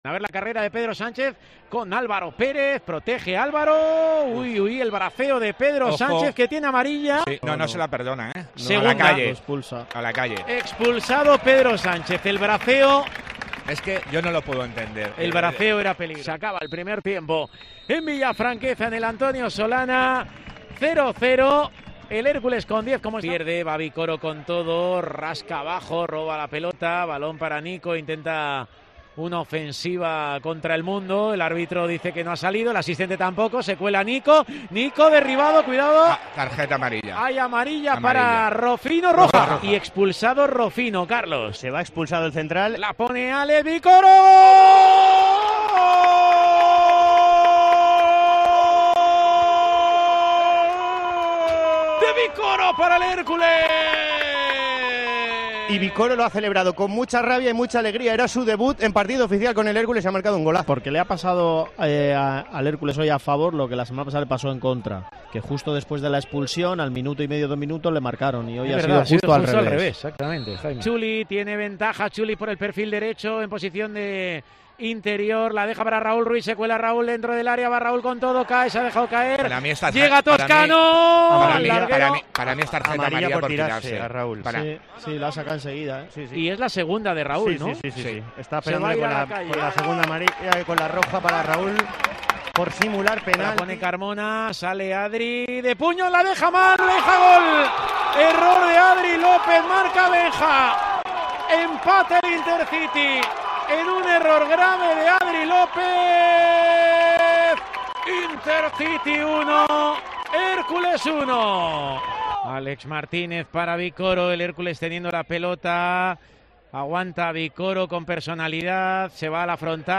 Así sonó la victoria del Hércules ante el Intercity en Tiempo de Juego Alicante